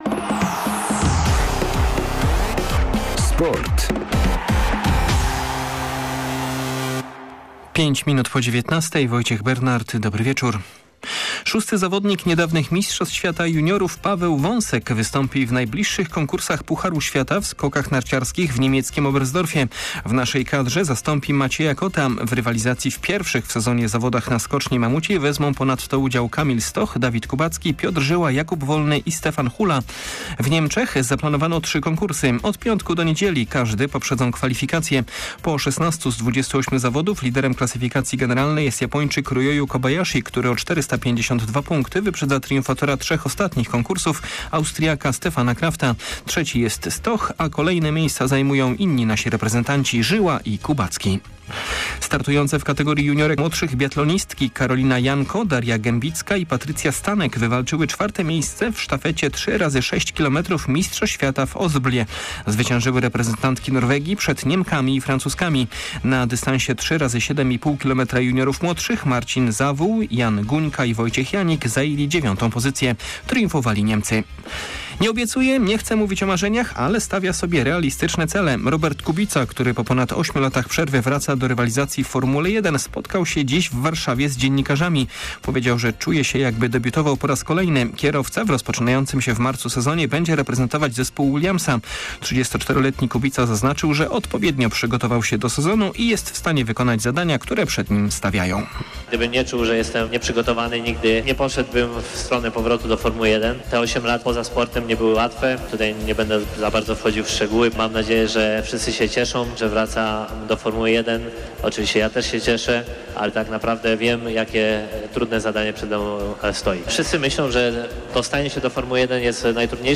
29.01. SERWIS SPORTOWY GODZ. 19:05
W naszym wieczornym serwisie między innymi informacje z zimowych aren sportowych. Posłuchamy także Roberta Kubicy, który spotkał się z dziennikarzami przed swoim powrotem na tory Formuły 1.